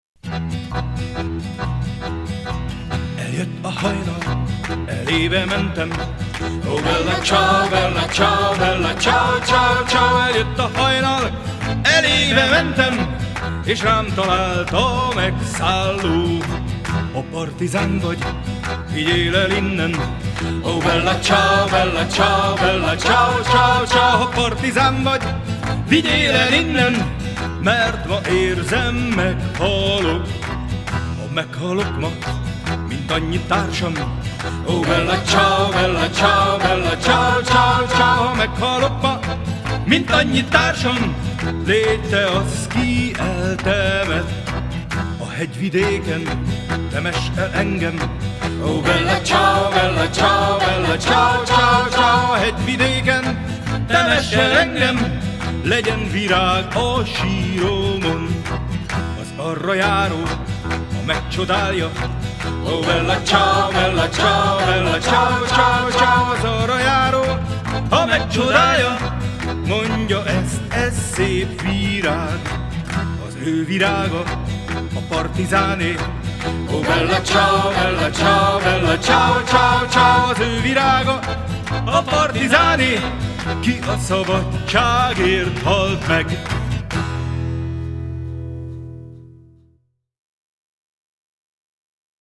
Folklore siciliano, tarantella